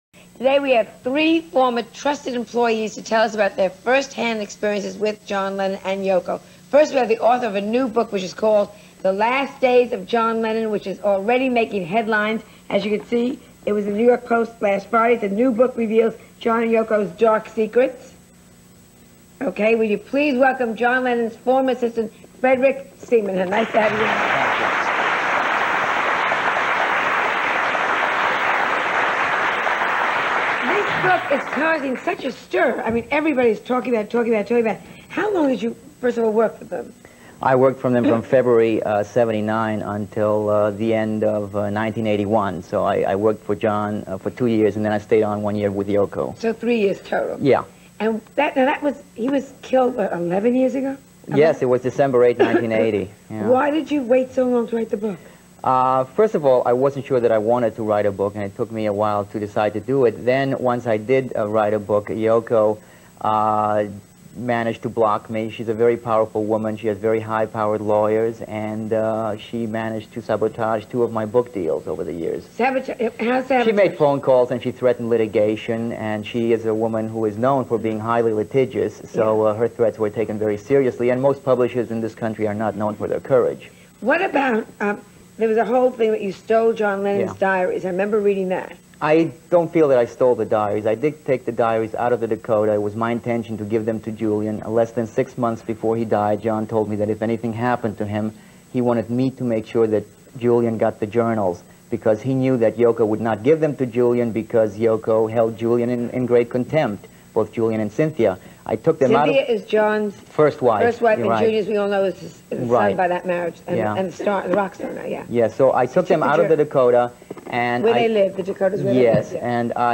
1991 Interview